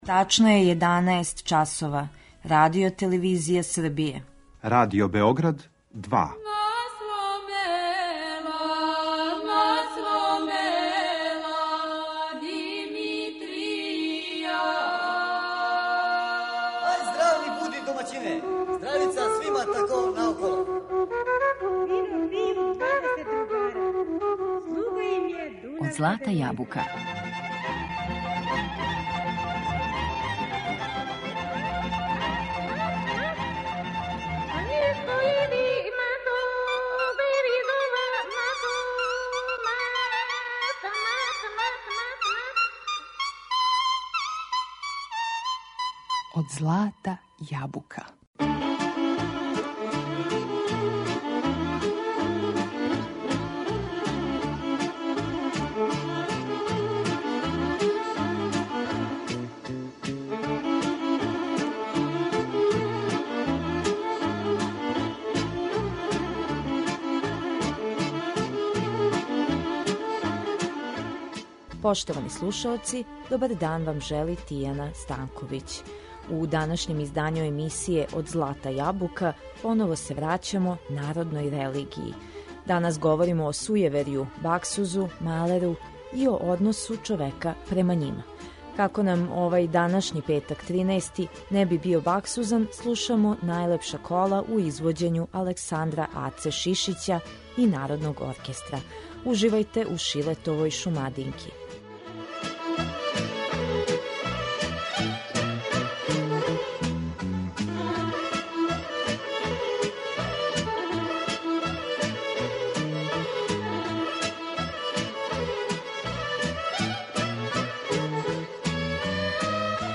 Данас говоримо о сујеверју, тачније о баксузу и малеру и односу човека према њима. Слушаћемо најлепша кола у извођењу Александра Аце Шишића и Народног оркестра РТС.